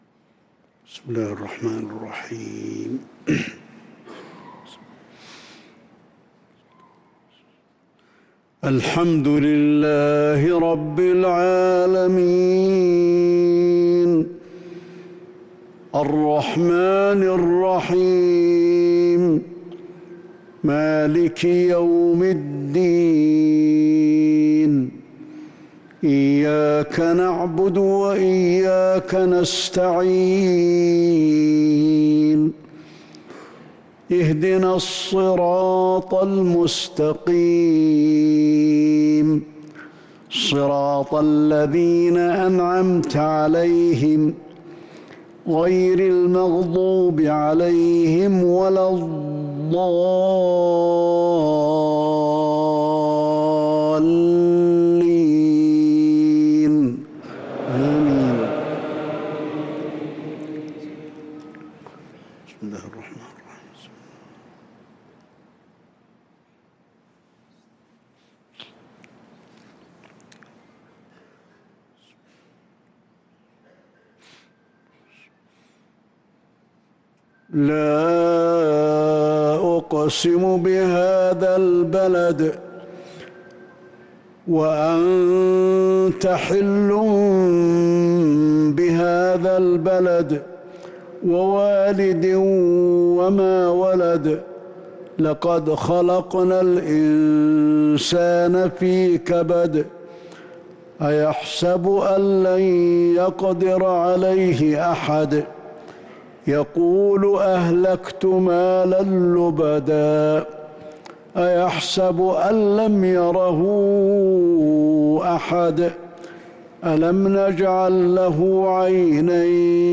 صلاة العشاء للقارئ علي الحذيفي 17 ذو القعدة 1443 هـ
تِلَاوَات الْحَرَمَيْن .